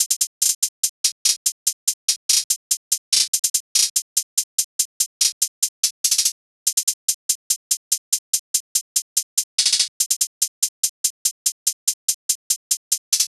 SOUTHSIDE_beat_loop_red_hihat_144.wav.wav